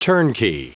Prononciation du mot turnkey en anglais (fichier audio)
Prononciation du mot : turnkey